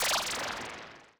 portal_1.ogg